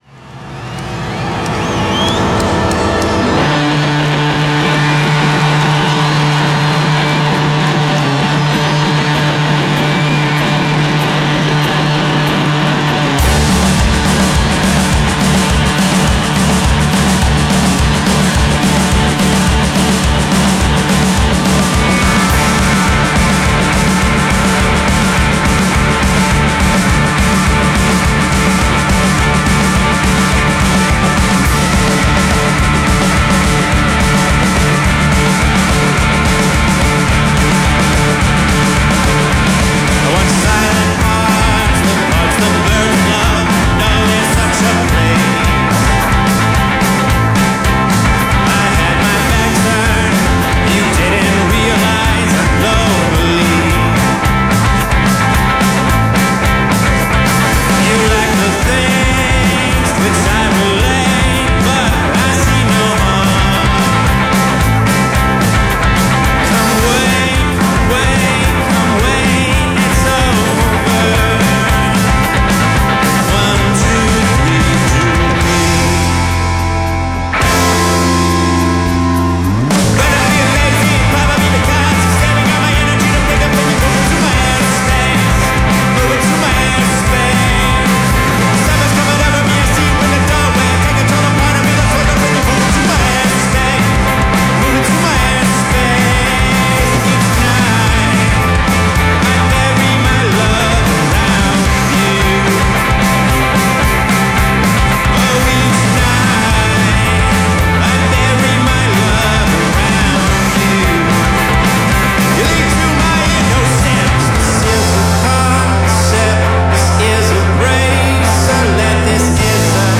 live
American rock band
post-punk revival